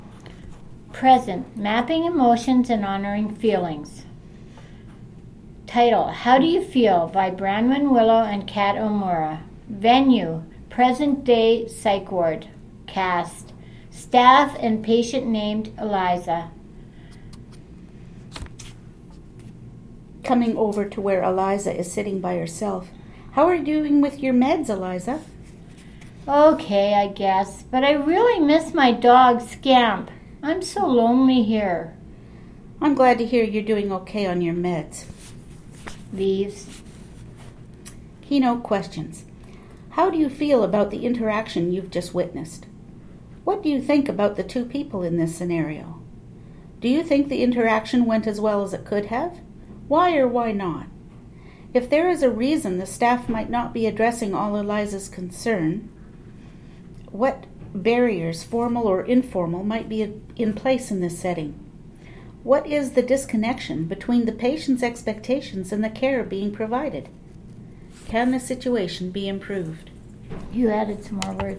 The components for this unit are three short plays, fictionalized accounts of mental health experiences in the past, present, and imagined future.